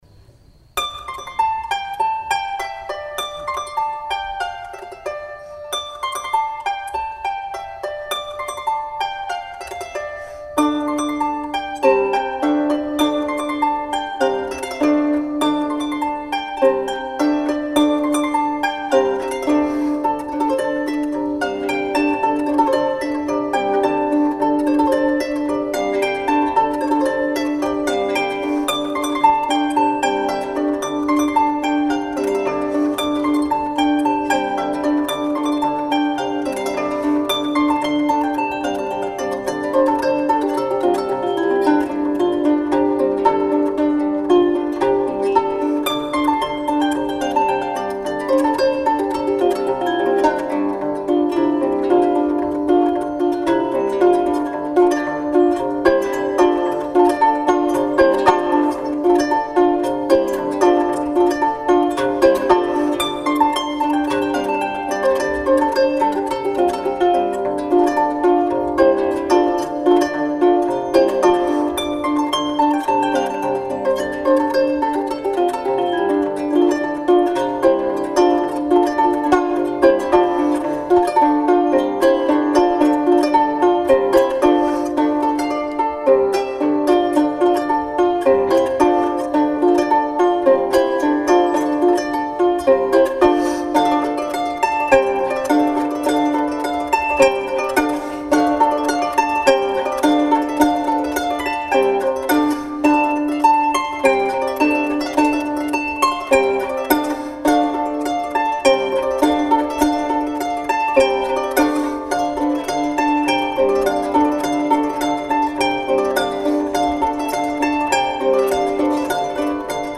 Live
(Celtic harp)  2'282.26 MB1.70 Eur